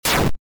railgun.ogg